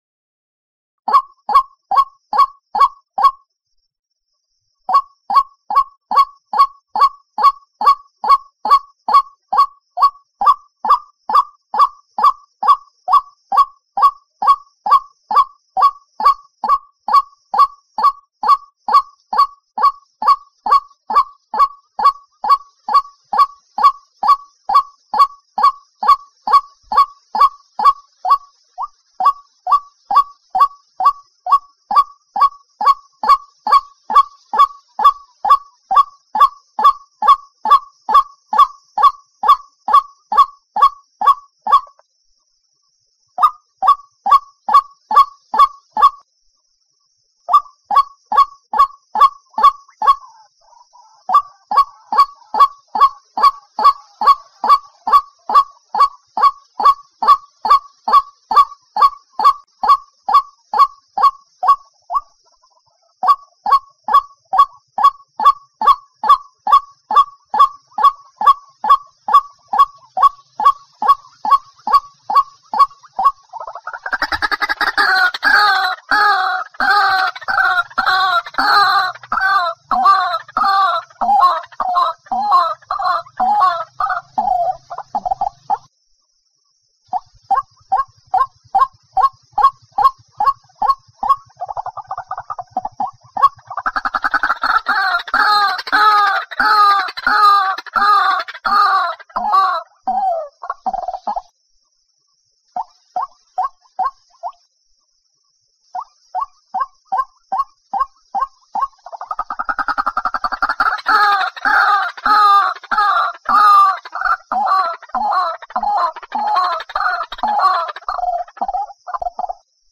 Suara burung Ruak Ruak
Kategori: Suara burung
Keterangan: Anda bisa mendengarkan dengan menekan tombol Play yang ada di atas atau Anda bisa mengunduh mp3 dengan suara ruak-ruak yang jernih, suara Burung Wak-Wak, dan Burung Ruak-Ruak Gacor terbaik, bebas dari suara gangguan dan iklan, untuk komputer atau telepon Anda, melalui link unduhan yang tersedia di bawah ini.
tieng-chim-quoc-keu-www_tiengdong_com.mp3